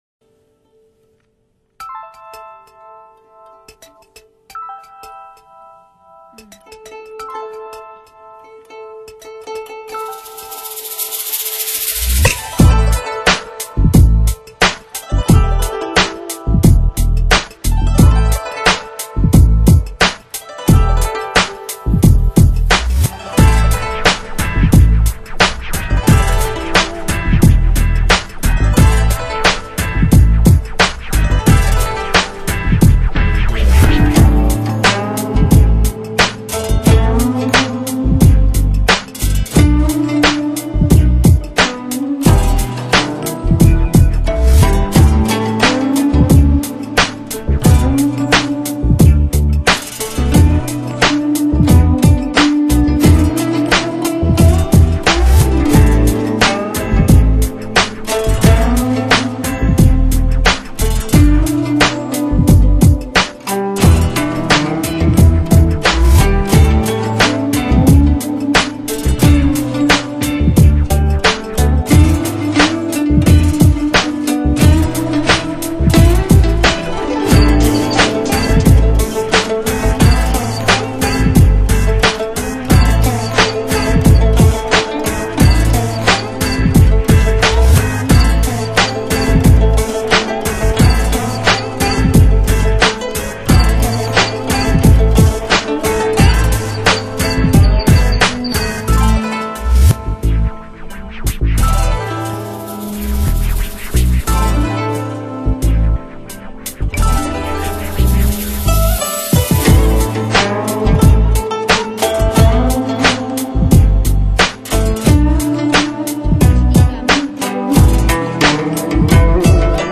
一种全新理念的酒吧音乐，时尚、刺激、有情调、氛围好，音乐风格是多样化、风格化，
清晰分明，扩声均匀，中高不刺，温暖柔和。